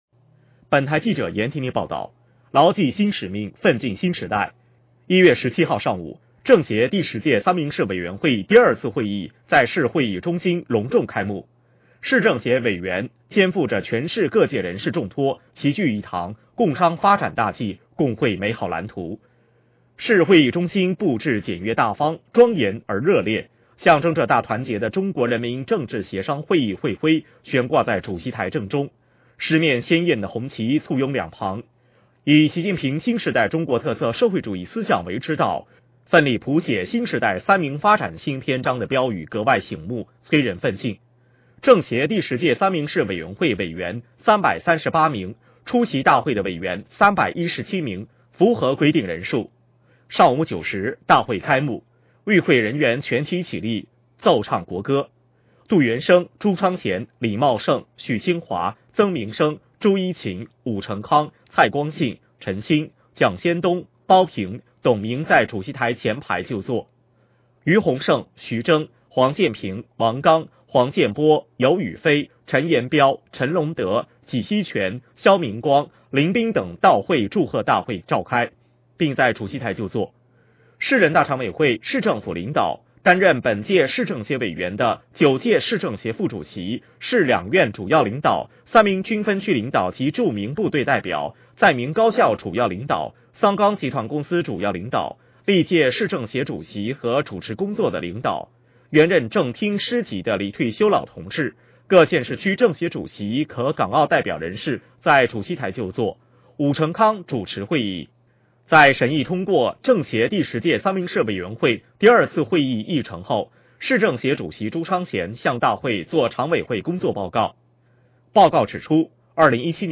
政协第十届三明市委员会第二次会议隆重开幕 _ 音频播报 _ 三明市政协